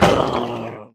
Minecraft Version Minecraft Version snapshot Latest Release | Latest Snapshot snapshot / assets / minecraft / sounds / entity / shulker / death4.ogg Compare With Compare With Latest Release | Latest Snapshot